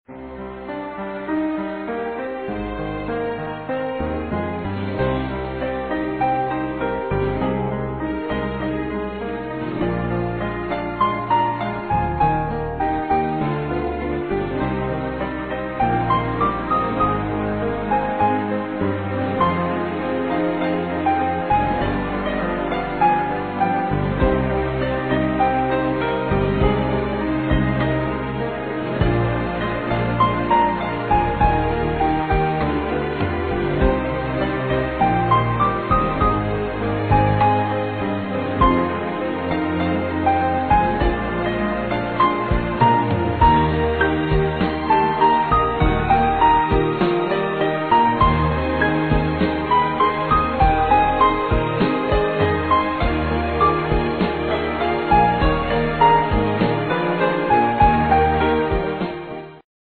sonnerie de téléphone 😃
sonnerie.mp3